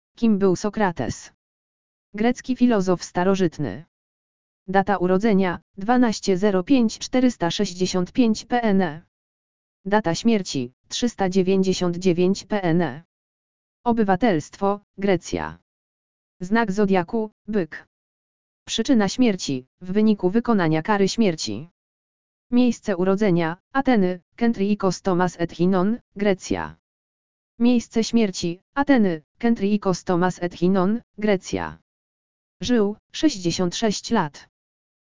audio_lektor_urodziny_sokrates.mp3